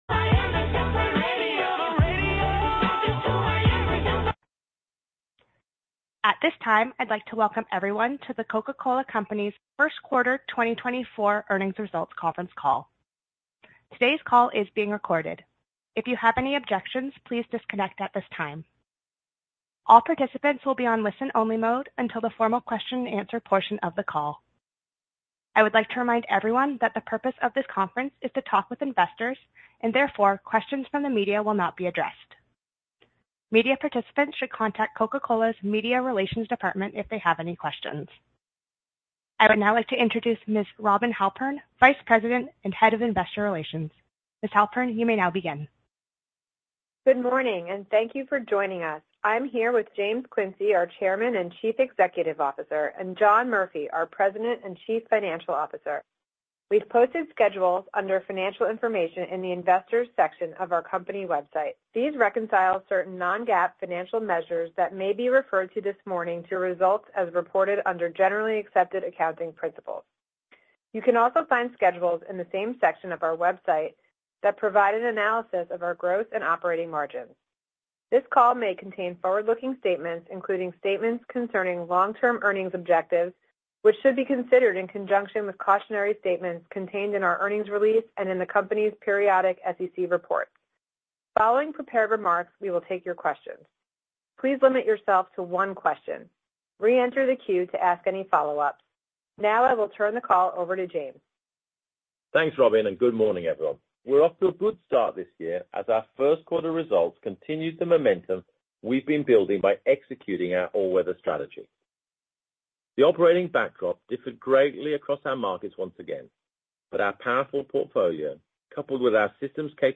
Earnings Call Q1 2024 Audio